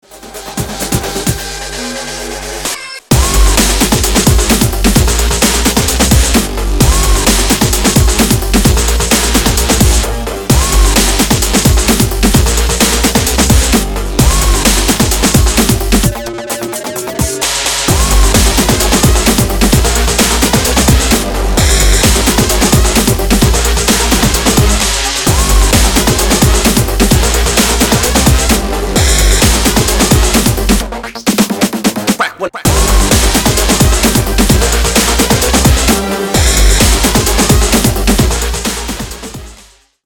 Ремикс # Электроника
клубные